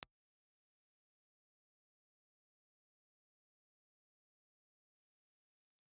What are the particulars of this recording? Impulse Response file of the rear of the RGD ribbon microphone. RGD_Ribbon_IR_Rear.wav There is a pronounced difference between the two sides of the microphone.